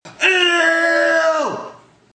Tags: Jeapordy Game Show Sounds Effect